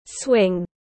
Cái xích đu tiếng anh gọi là swing, phiên âm tiếng anh đọc là /swɪŋ/
Swing /swɪŋ/